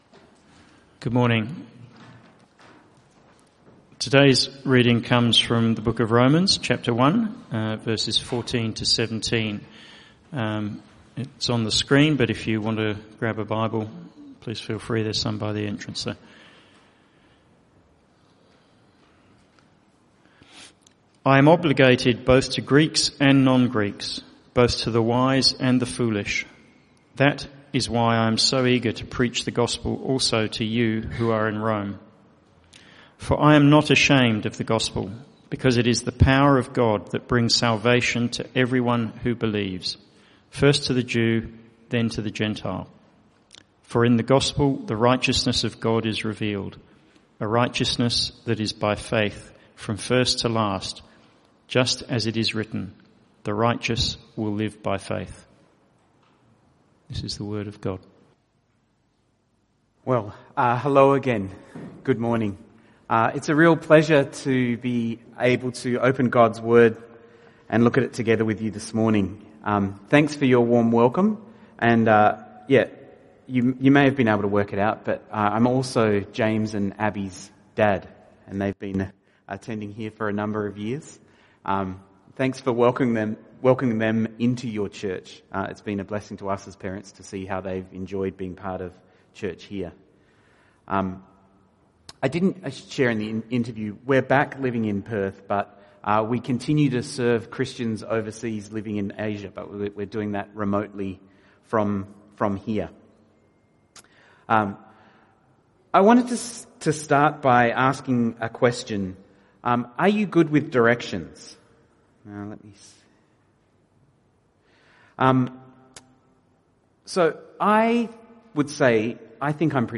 Passage: Romans 1:14-17 Type: Sermons